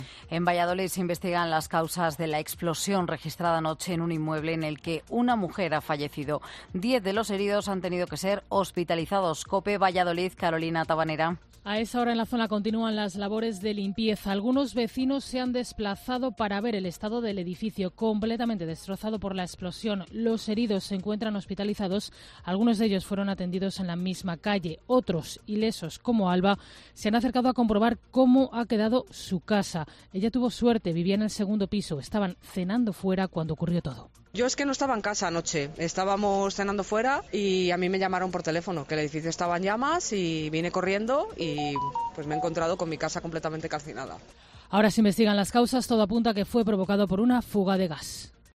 Vecina de Valladolid afectada por la explosión de un edificio, en COPE: "Mi casa está totalmente calcinada"